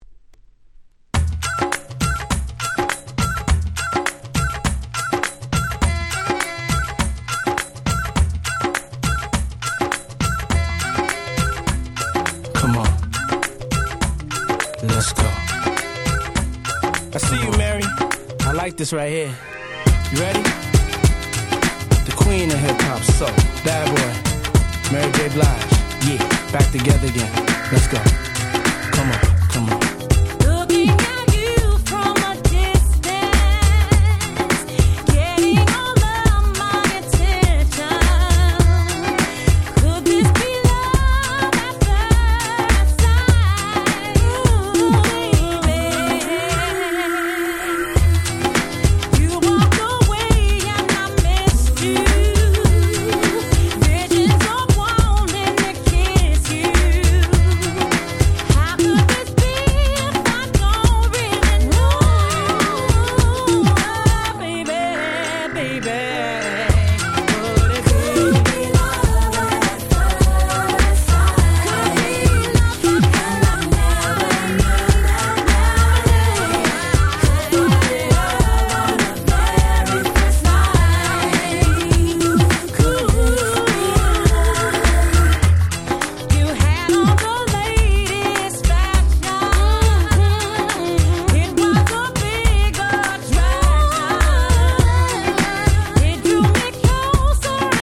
03' Smash Hit R&B !!